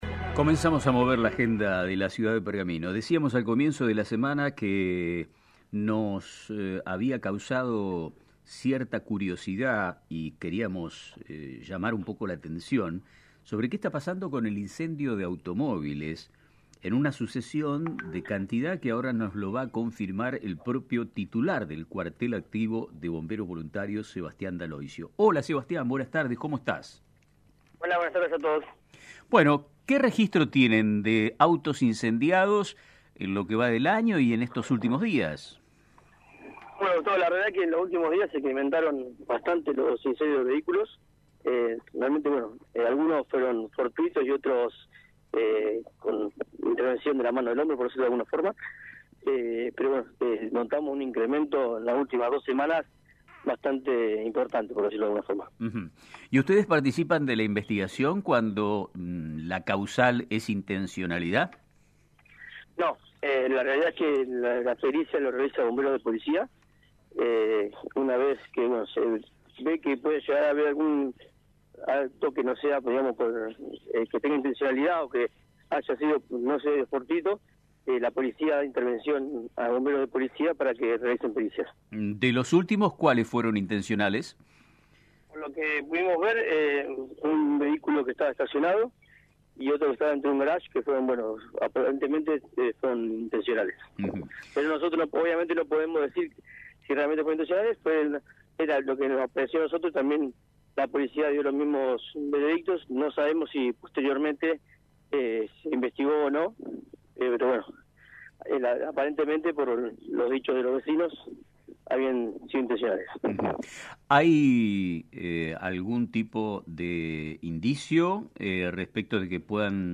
Aumento preocupante de incendios de autos en Pergamino: Entrevista